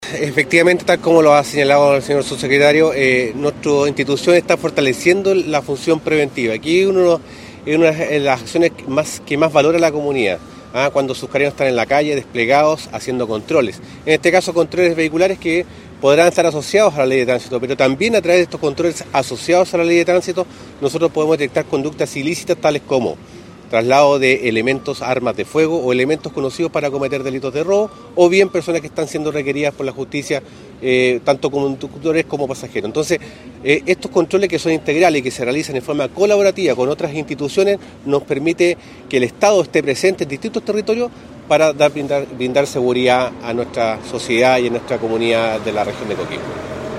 FISCALIZACION-VEHICULAR-General-Carabineros-Juan-Munoz.mp3